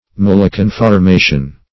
Maleconformation \Male*con`for*ma"tion\, n.